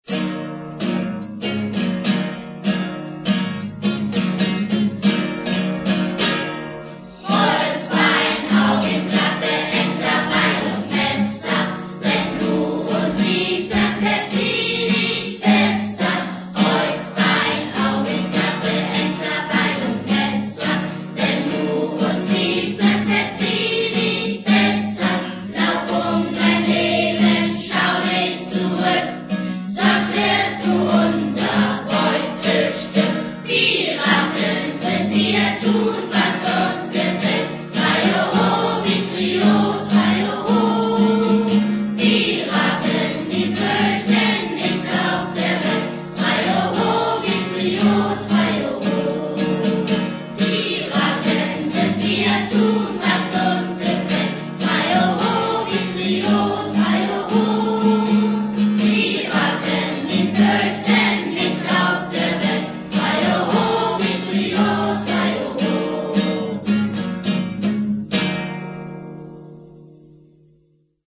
Piratenlied.mp3 (277 KB)  Aufnahme des Piratenliedes, gesungen von der Klasse 4b